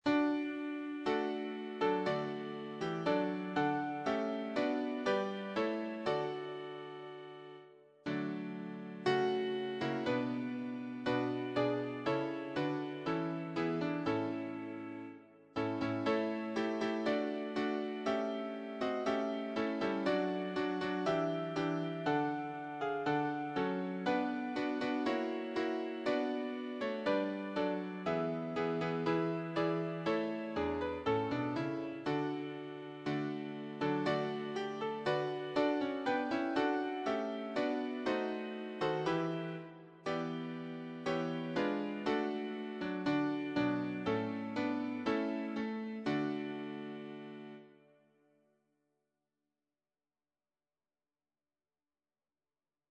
choir SATB
Anthem